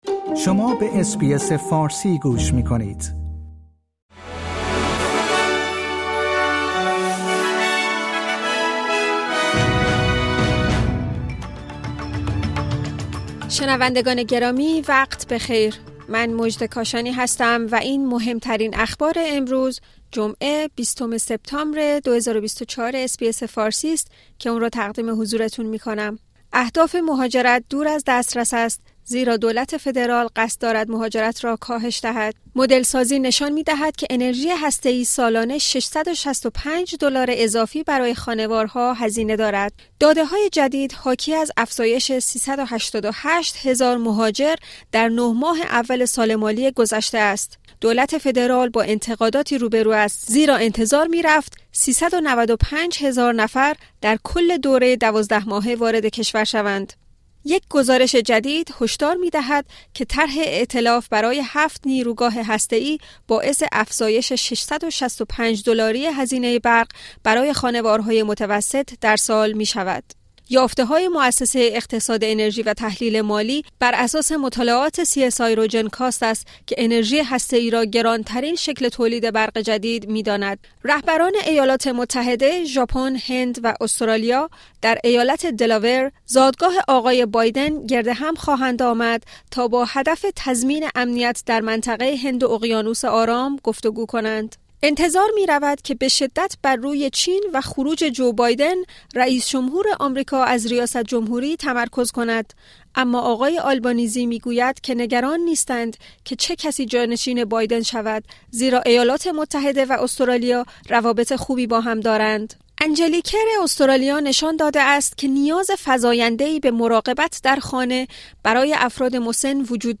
در این پادکست خبری مهمترین اخبار استرالیا در روز جمعه ۲۰ سپتامبر ۲۰۲۴ ارائه شده است.